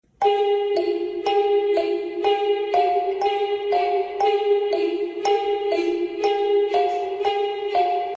Kalimba Tutorial